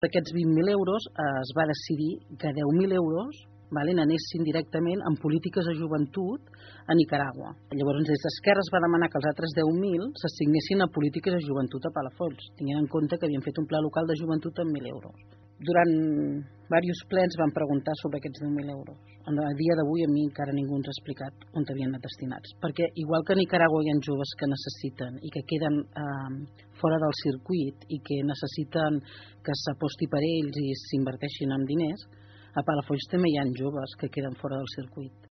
Durant l’entrevista, la regidora va explicar la posició que manté la seva formació respecte als recursos que l’Ajuntament destina a joventut.